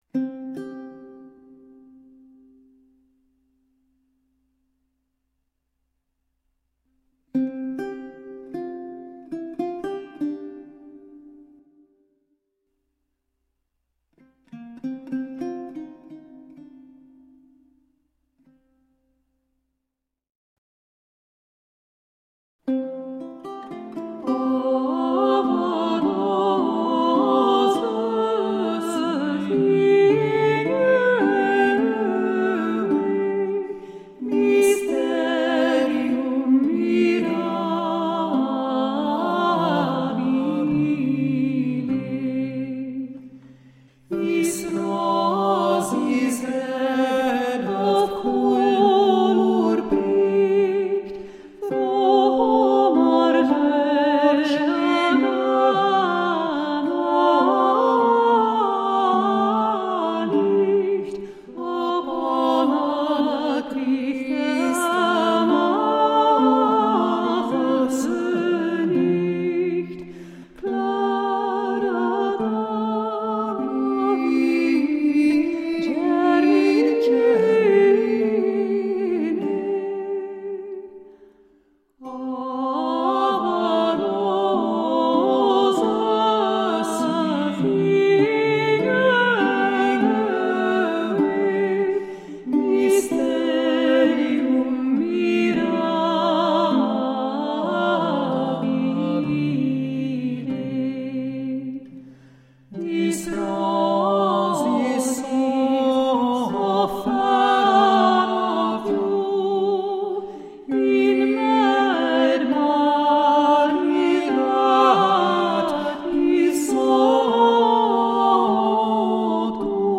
Late-medieval vocal and instrumental music.